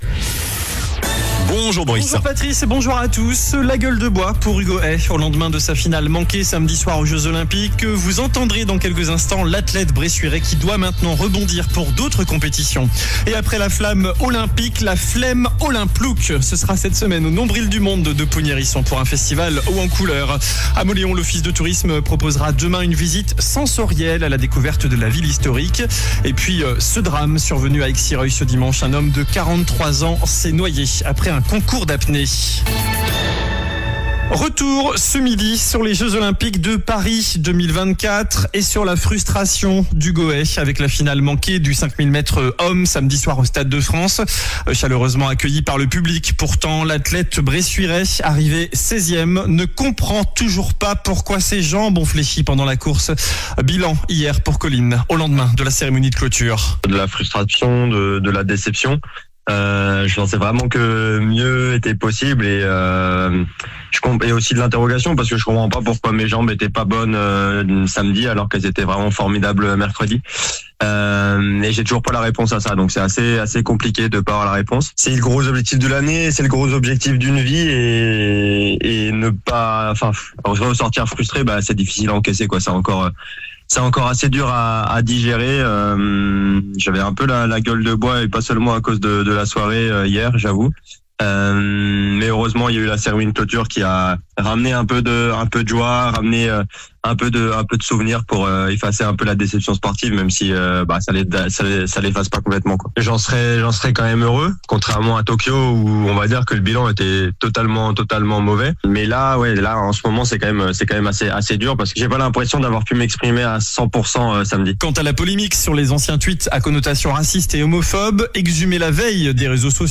JOURNAL DU MARDI 13 AOÛT ( MIDI )